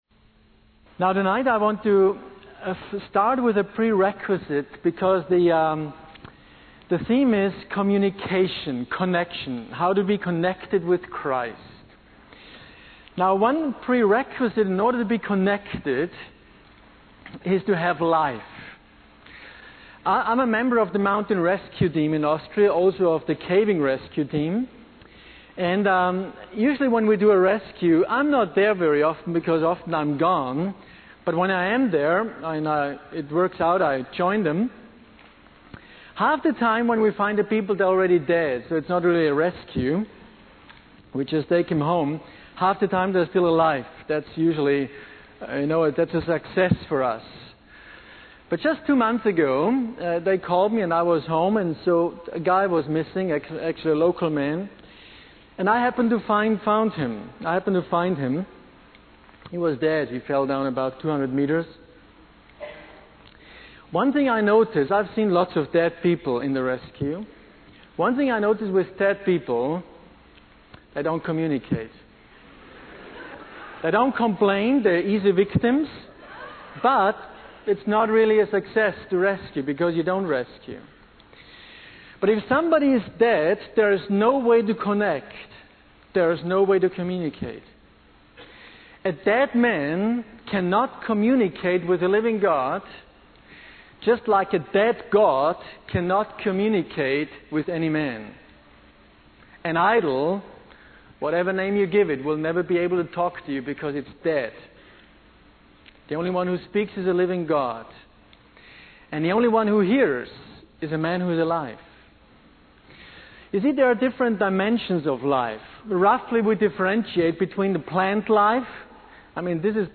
In this sermon, the speaker discusses the importance of hearing the voice of God and being connected with Christ. He emphasizes the need to renew our thinking patterns and let go of our old ways of life. The speaker highlights how Jesus motivated people with love rather than threats, and challenges the audience to examine their own behavior towards their spouses and children.